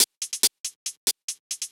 UHH_ElectroHatB_140-01.wav